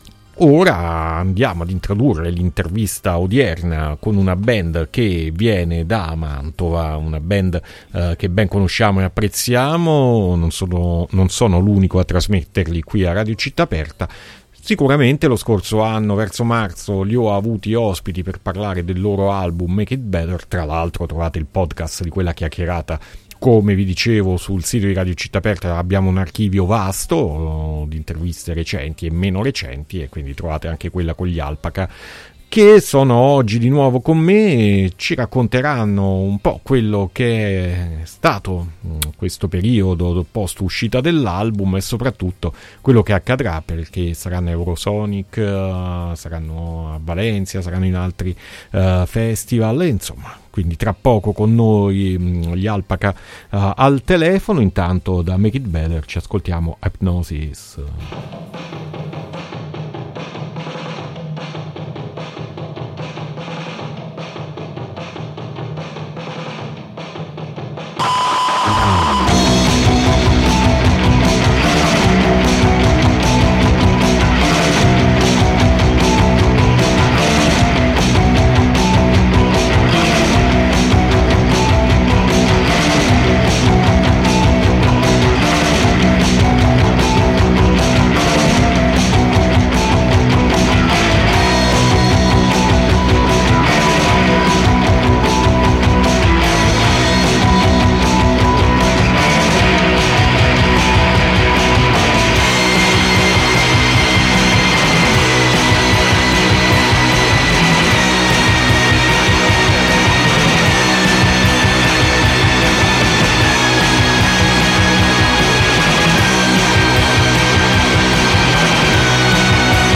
Intervista A/LPACA ad Alternitalia 4-2-2022